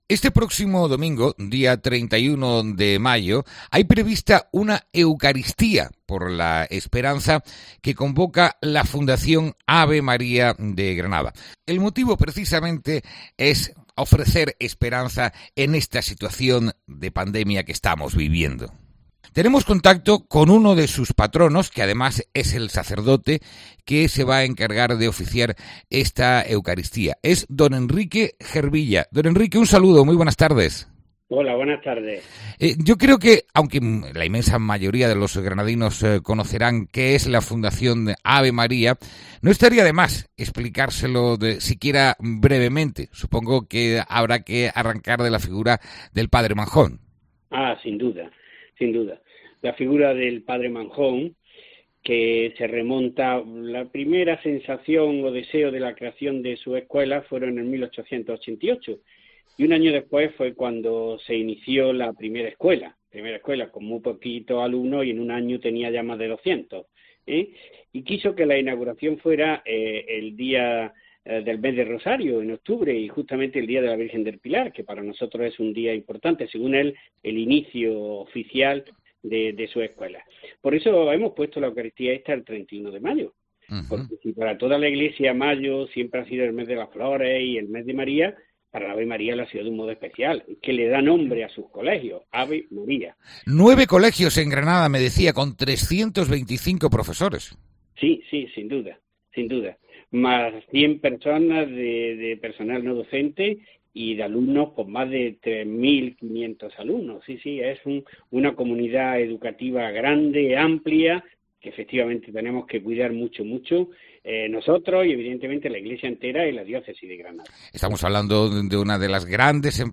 En el audio que acompaña esta información, tienen una entrevista